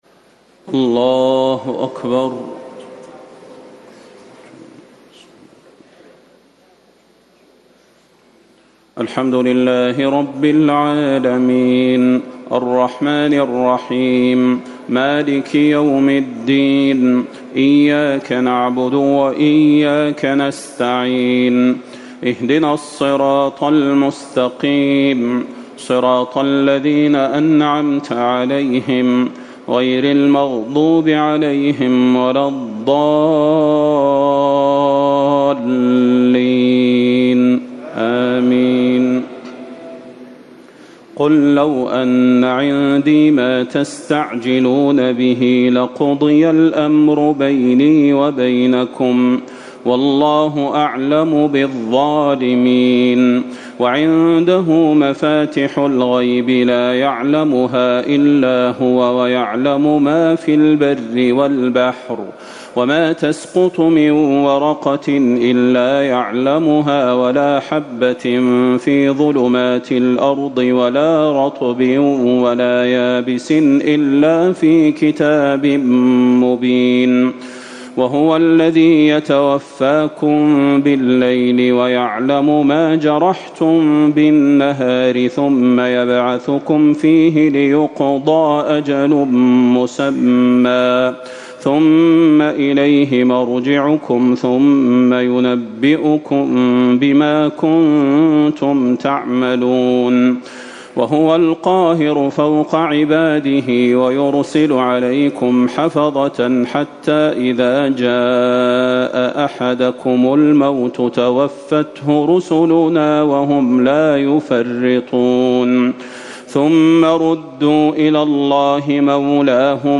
تهجد ٢٧ رمضان ١٤٤٠ من سورة الأنعام 58 - 111 > تراويح الحرم النبوي عام 1440 🕌 > التراويح - تلاوات الحرمين